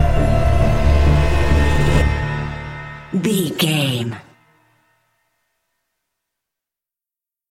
Aeolian/Minor
D
synthesiser
percussion
ominous
dark
suspense
haunting
creepy